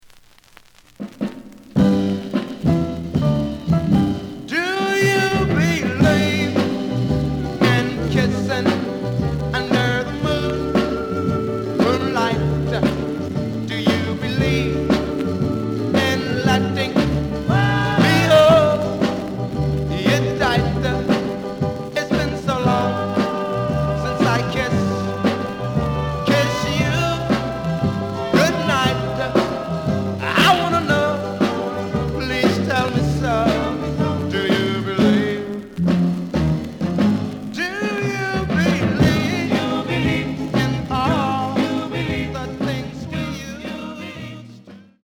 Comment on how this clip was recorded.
The listen sample is recorded from the actual item.